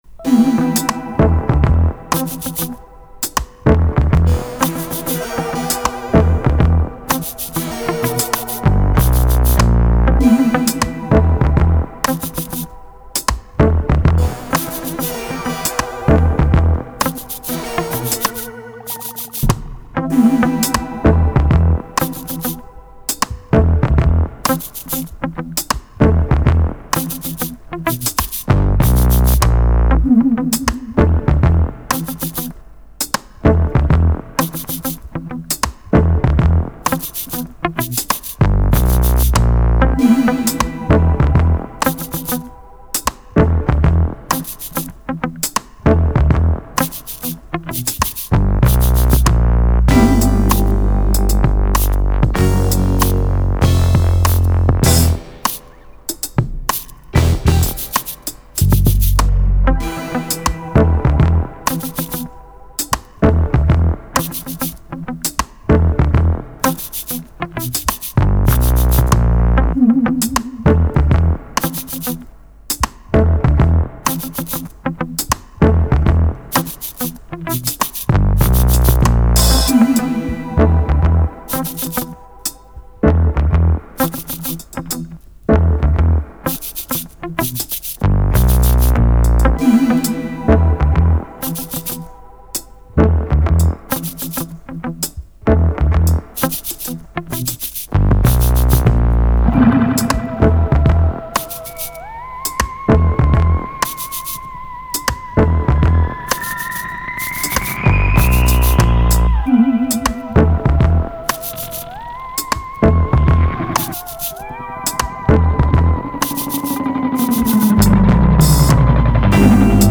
Haunting harpsichord hip hop beat with cool quirkiness.